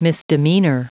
Prononciation du mot misdemeanor en anglais (fichier audio)
Prononciation du mot : misdemeanor